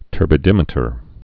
(tûrbĭ-dĭmĭ-tər)